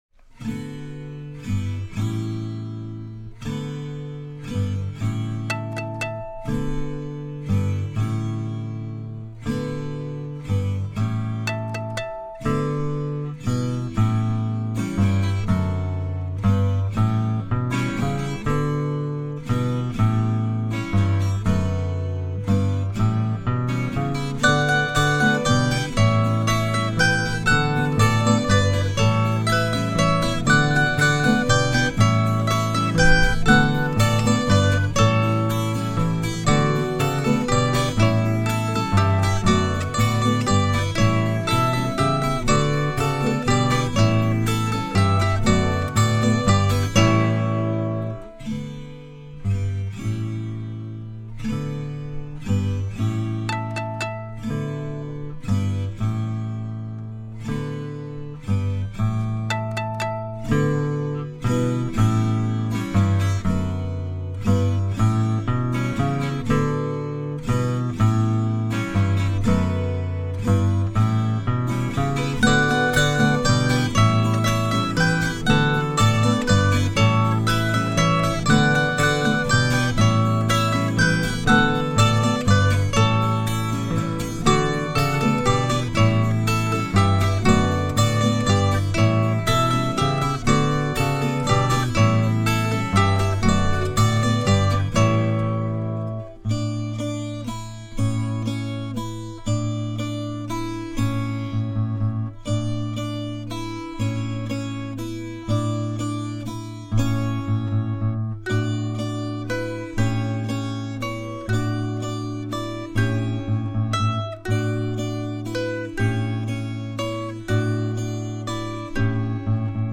es una obra alegre, con un tempo sugerido rápido